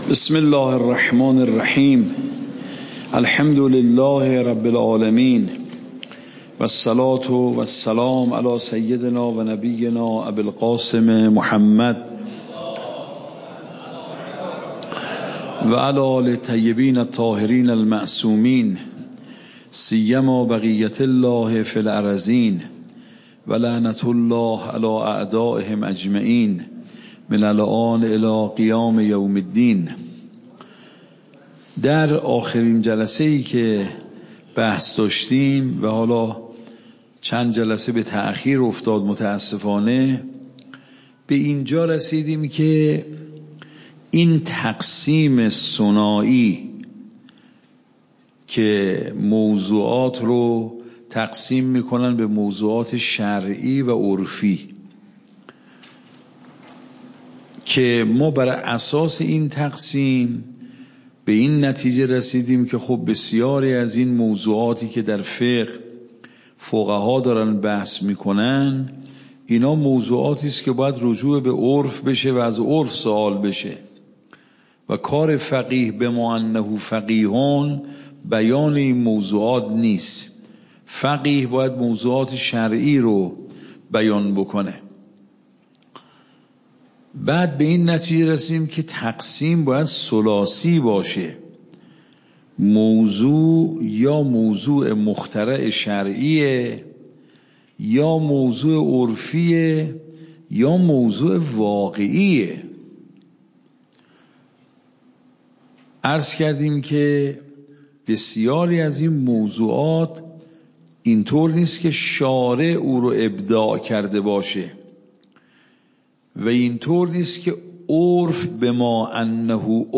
صوت درس